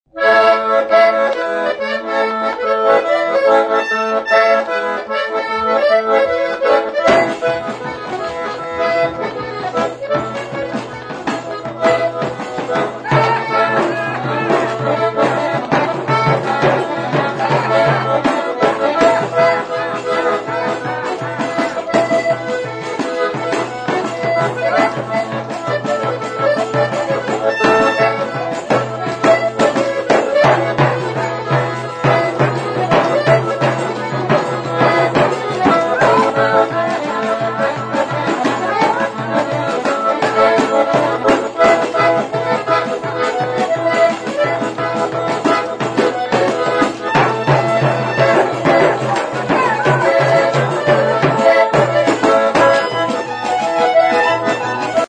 Folk (248)